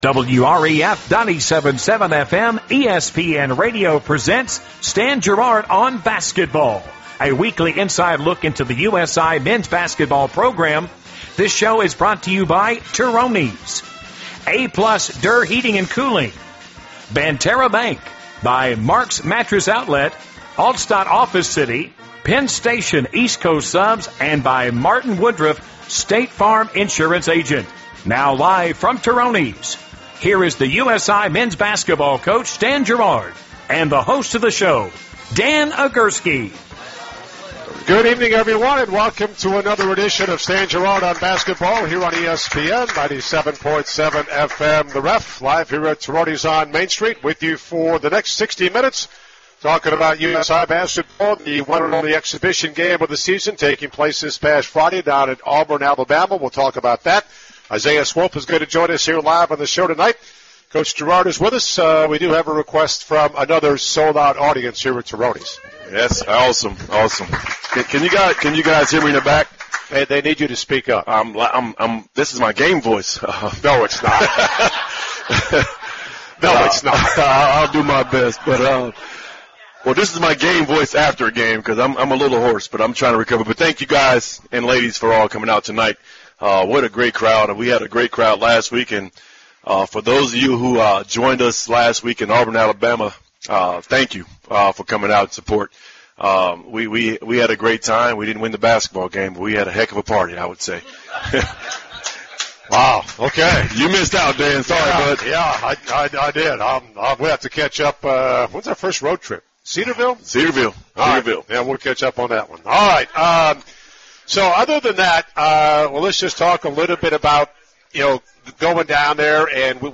live from Turoni's on Main Steet and on ESPN 97.7FM